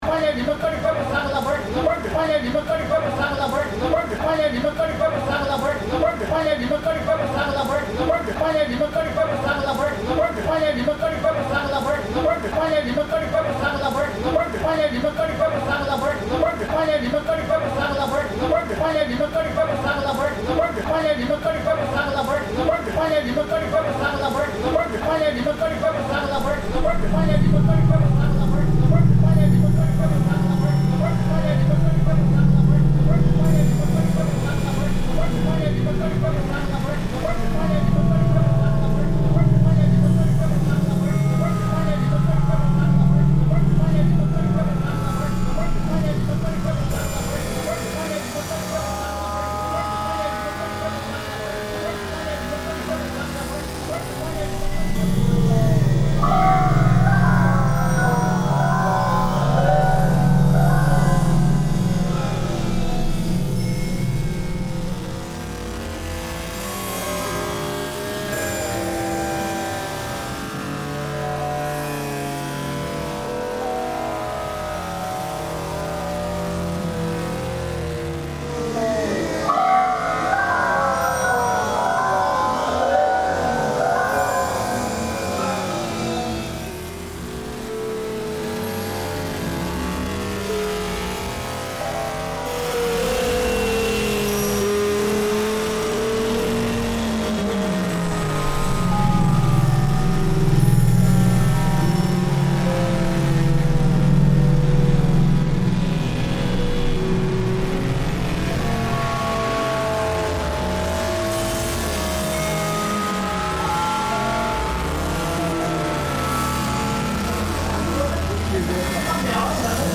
Wuhan night market reimagined